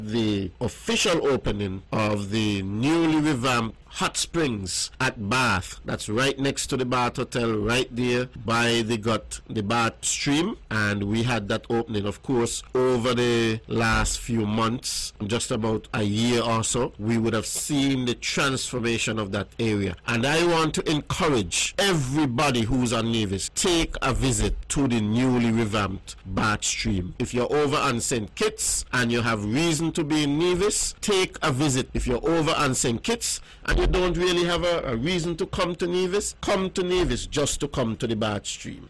Minister of Youth and Sports, Hon. Troy Liburd hosted Wednesday night’s airing of the “On The Mark” show on VON Radio where he spoke of the revamping of Nevis’ Bath Hot Springs.